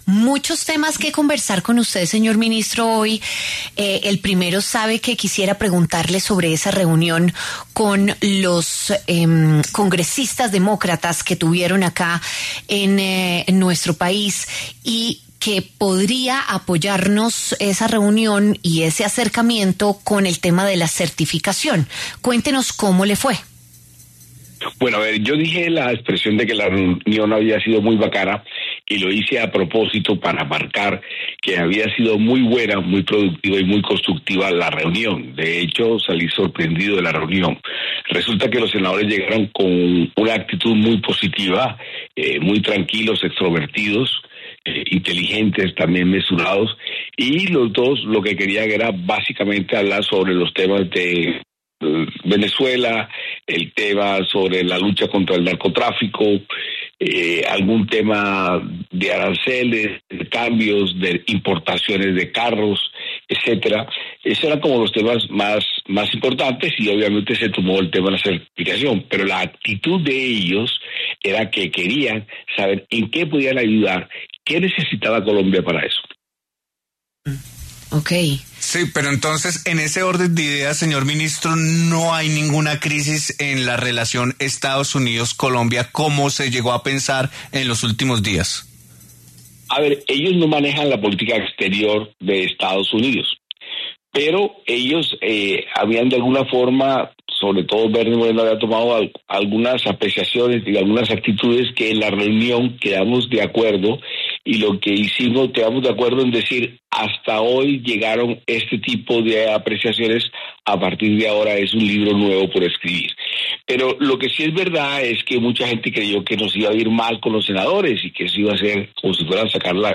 Armando Benedetti, ministro del Interior, se refirió en La W a la reunión del presidente Gustavo Petro con congresistas estadounidenses.